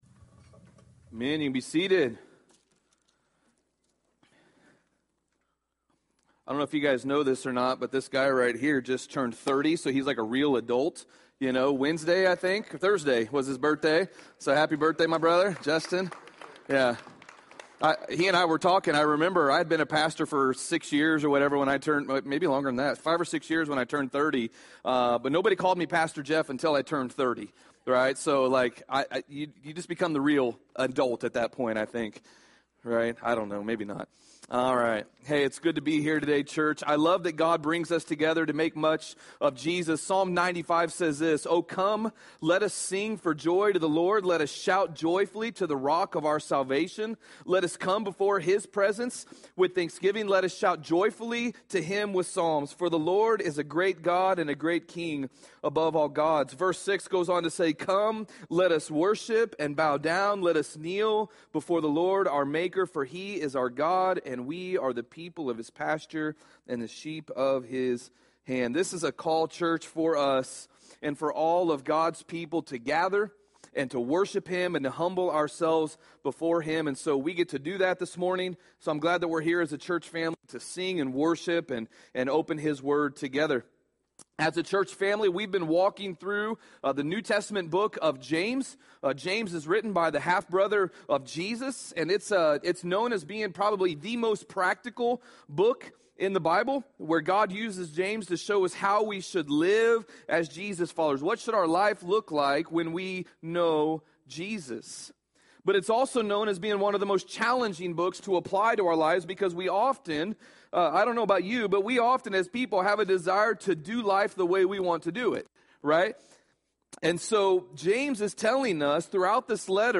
Sermon+3.06.22.mp3